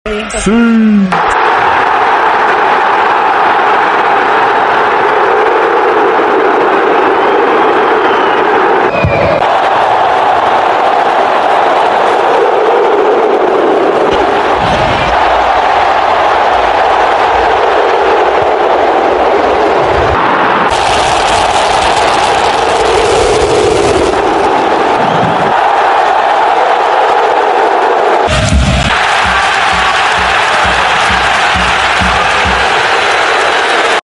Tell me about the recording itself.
You too can experience the original live sound: Siuuuuuuuu!